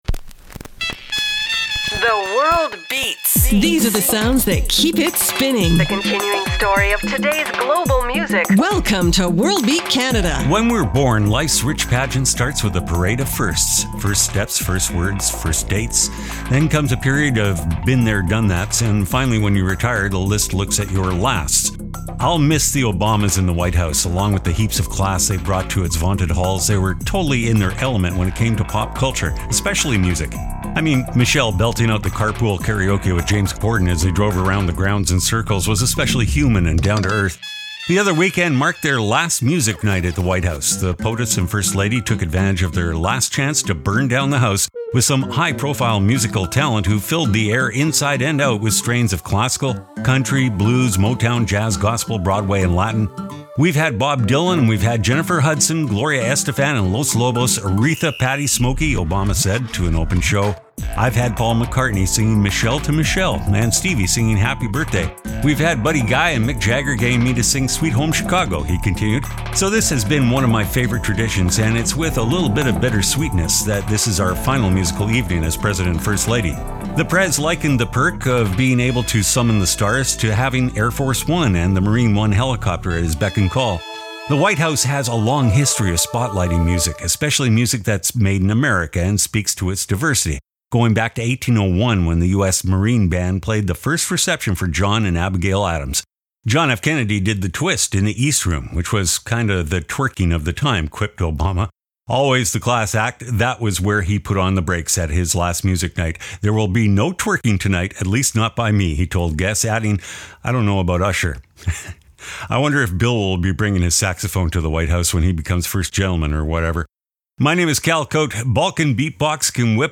exciting contemporary global music alternative to jukebox radio